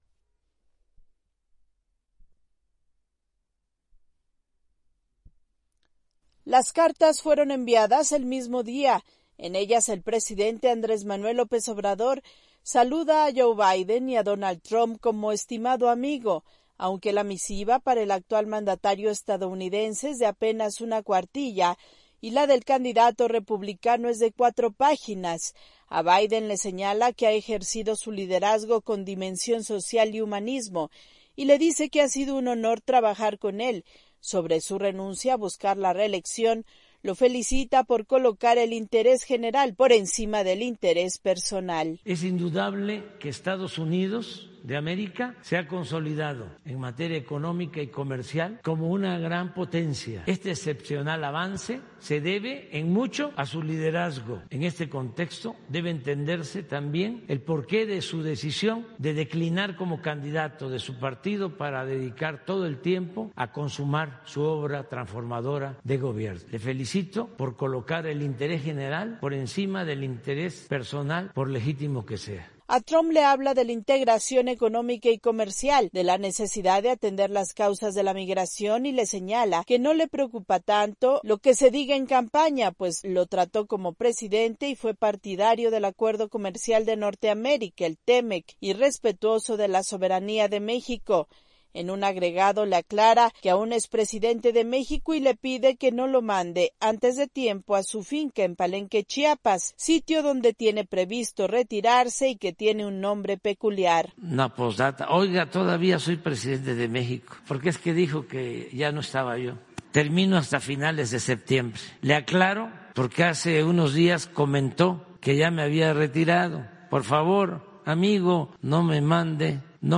El presidente mexicano López Obrador entabló comunicación con su homólogo Joe Biden después de que renunció a buscar la reelección y también lo hizo con el candidato republicano Donald Trump, estableciendo un acercamiento. Desde México informa la corresponsal de la Voz de América